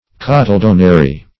Search Result for " cotyledonary" : The Collaborative International Dictionary of English v.0.48: Cotyledonary \Cot`y*led"on*a*ry\ (-?-r?), a. Having a cotyledon; tufted; as, the cotyledonary placenta of the cow.
cotyledonary.mp3